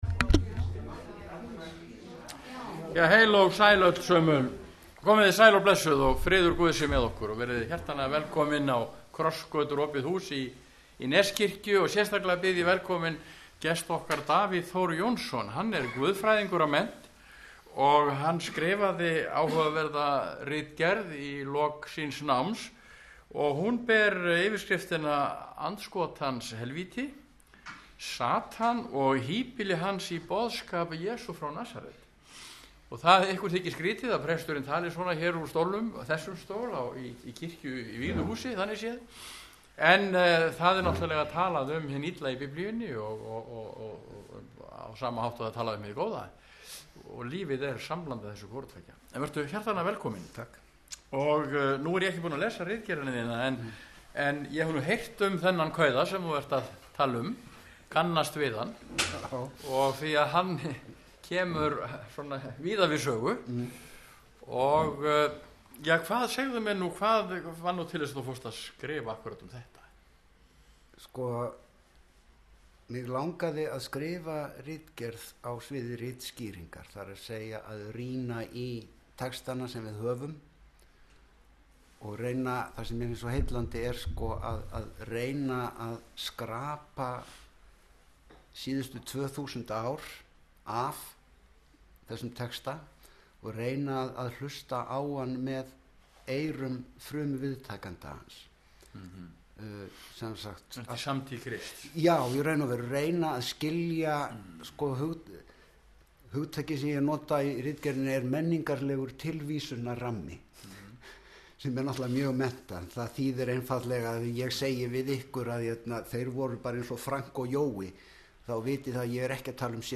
Samtalið er hins vegar hér fyrir neðan.
ræddi við hann á Krossgötum – Opnu húsi í Neskirkju